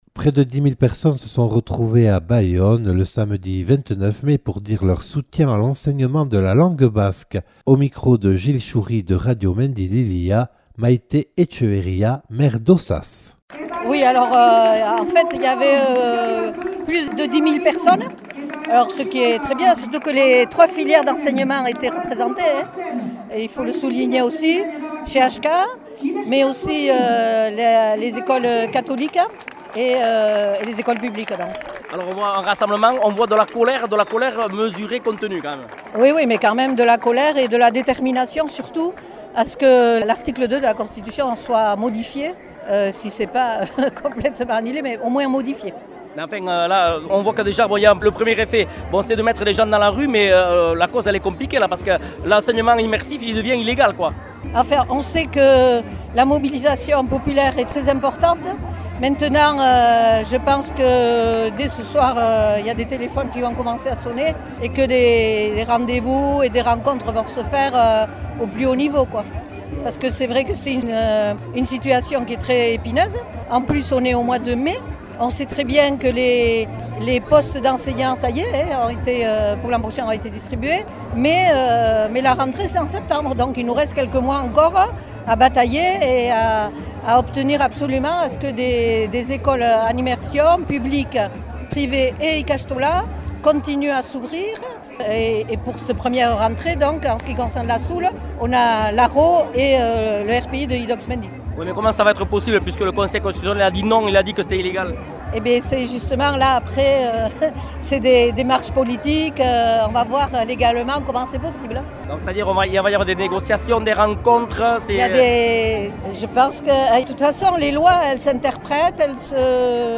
Près de 10 000 personnes se sont retrouvées à Bayonne samedi 29 mai pour dire leur soutien à l’enseignement de la langue basque.